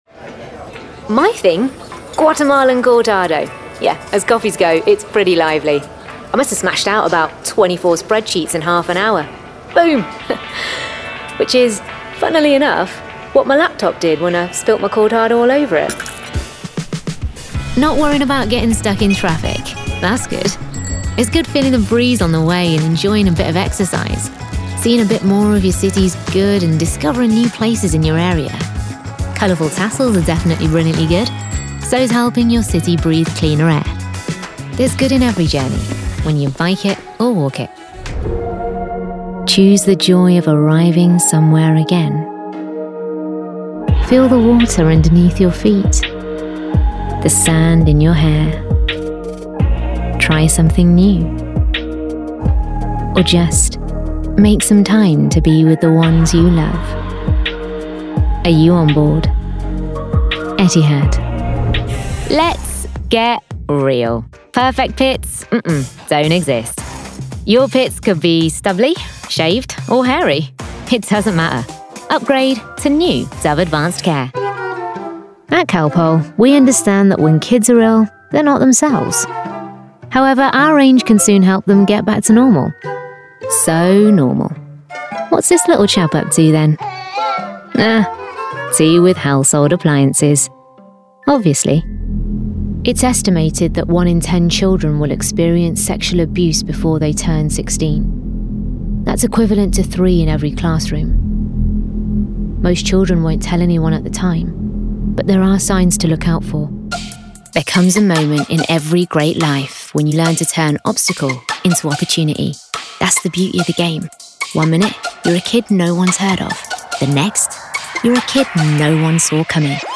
Engels (Brits)
Natuurlijk, Speels, Stedelijk, Vriendelijk, Warm
Commercieel